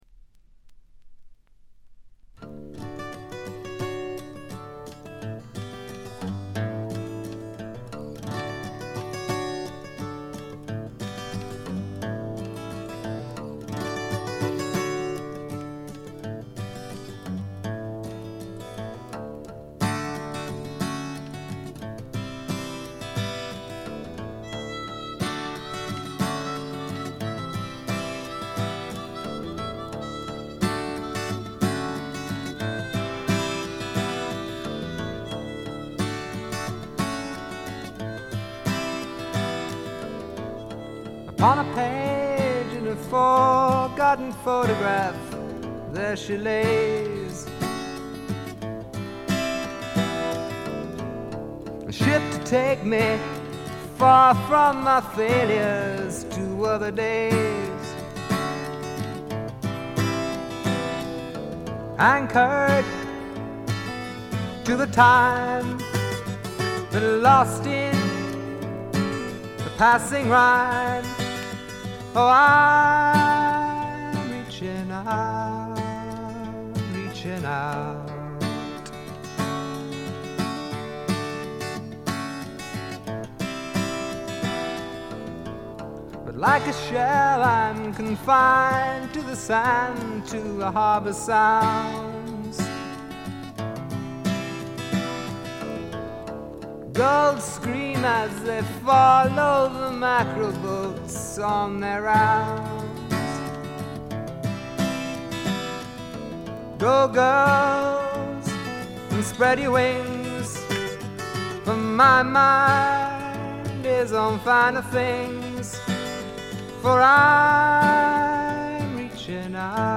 部分試聴ですが、軽微なチリプチ程度。
試聴曲は現品からの取り込み音源です。